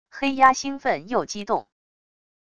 黑鸦兴奋又激动wav音频